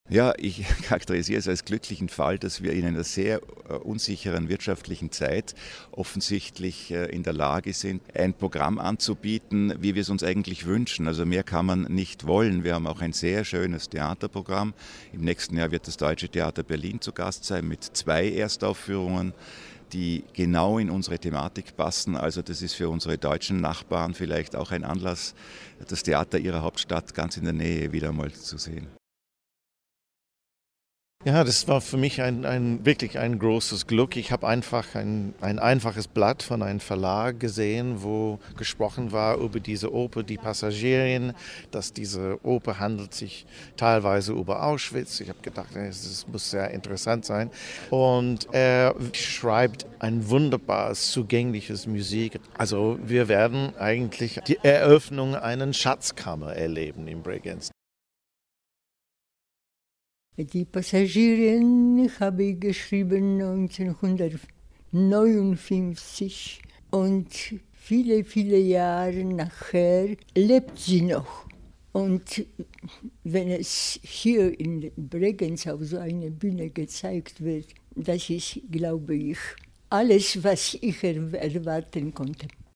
Audio PK Gesamtprogramm Feature